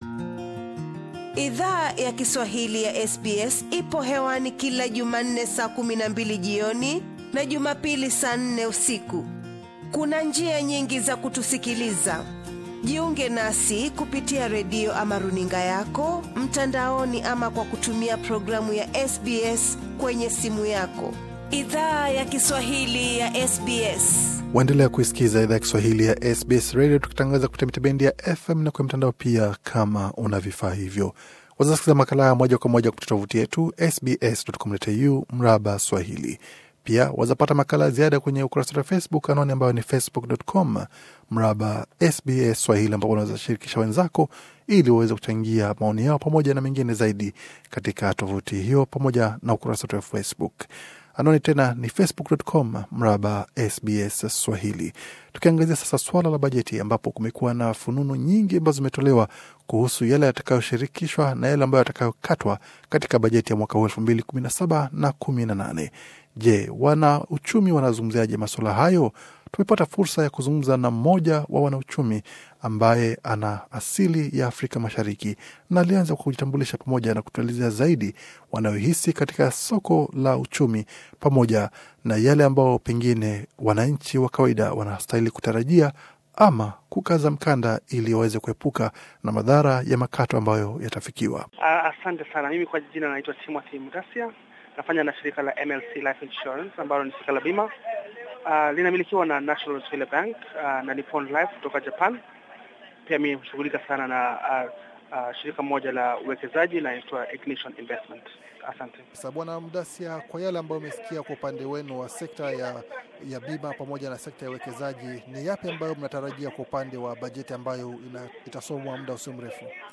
Masaa machache kabla ya bajeti ya 2017-18 kusomwa, SBS Swahili ilizungumza na mwanauchumi kuhusu, jinsi bajeti hiyo mpya ita wa athiri wa Australia. Bonyeza hapo juu kwa mahojiano kamili.